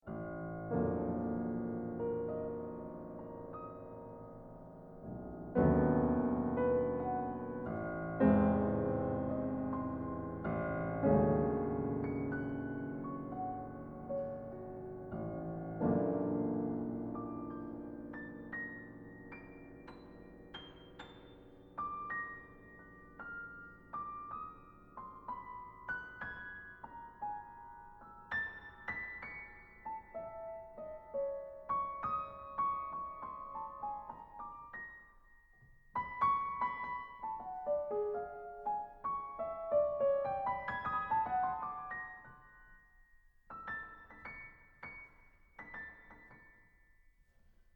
24 bit digital recording
piano